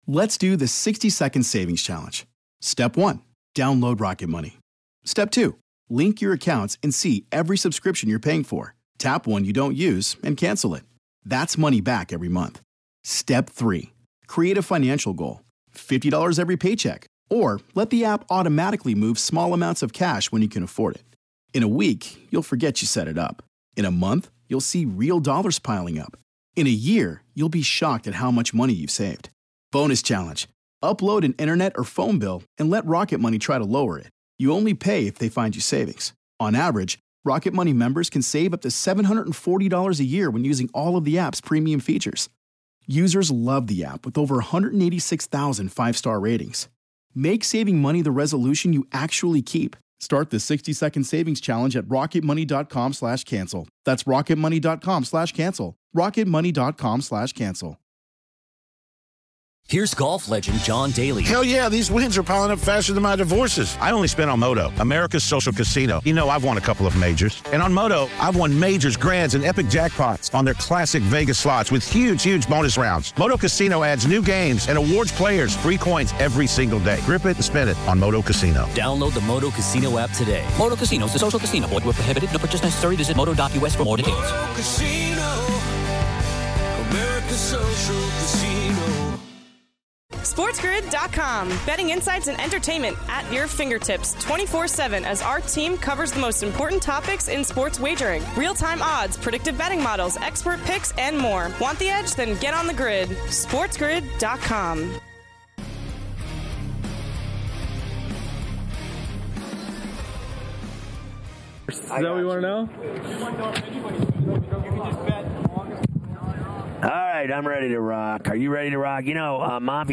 2/6 Hour 3: Ferrall Live From Radio Row in Las Vegas!
On this episode, Ferrall welcomes the biggest guests including Robbie Gould, Nick Lowry, and Dhani Jones all week live from Radio Row in Las Vegas as he prepares for Super Bowl LVIII!
Legendary sports shock jock Scott Ferrall takes the gaming world by storm with his “in your face” style, previewing the evening slate of games going over lines, totals and props, keeping you out of harms way and on the right side of the line.